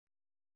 ♪ vāsi tōṛu